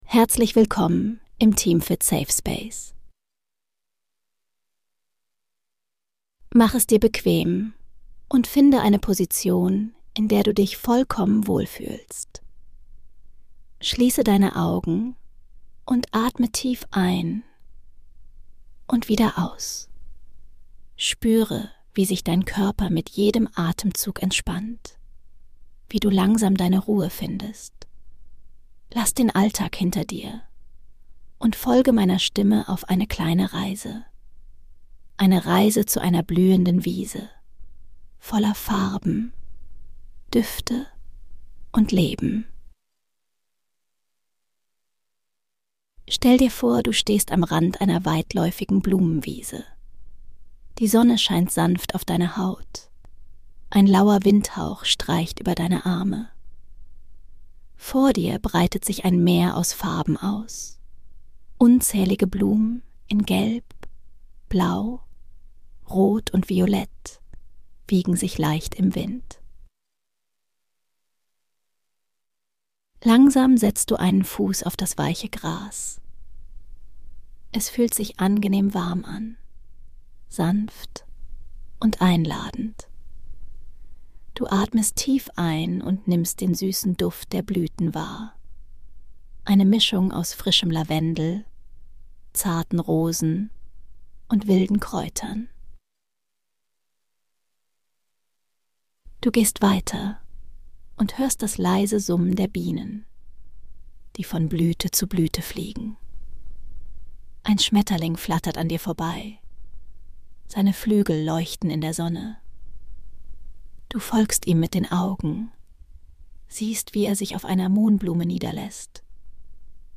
Eine sanfte Traumreise zu einer blühenden Wiese voller Farben, Düfte und Leben.